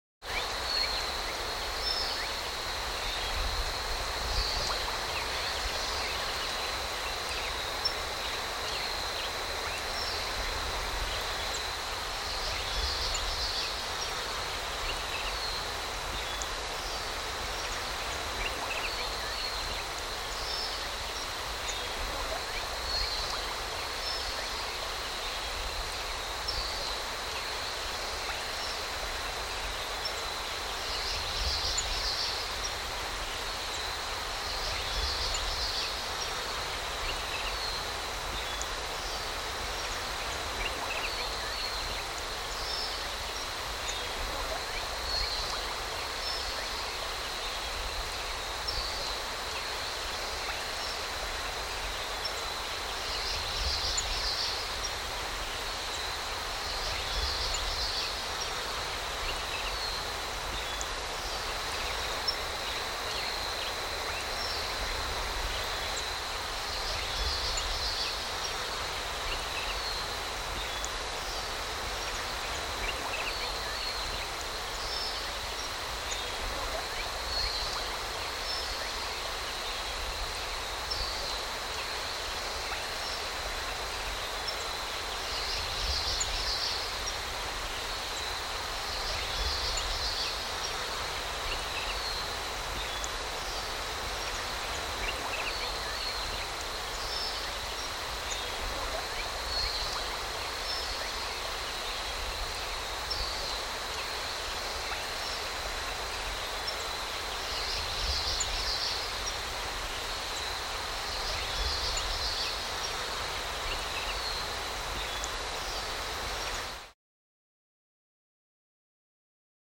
دانلود صدای پرنده 6 از ساعد نیوز با لینک مستقیم و کیفیت بالا
جلوه های صوتی
برچسب: دانلود آهنگ های افکت صوتی انسان و موجودات زنده دانلود آلبوم صدای پرندگان و حشرات از افکت صوتی انسان و موجودات زنده